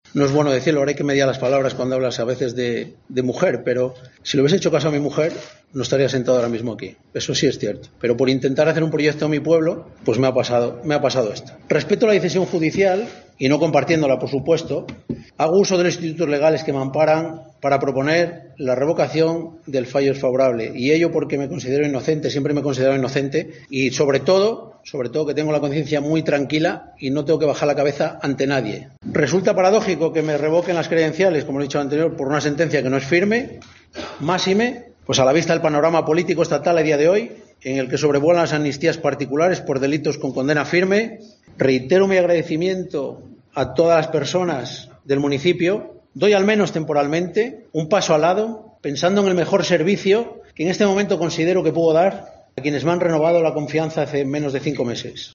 El alcalde de la localidad berciana de Cabañas Raras, Juan Marcos López, del PP, tras presentar su dimisión después de que la Junta Electoral de Zona de Ponferrada le revocase su acta de concejal, tras la sentencia de la Audiencia Provincial que le condenaba por un delito de gestión interesada y prevaricación administrativa, ha dado explicaciones en una comparecencia de prensa.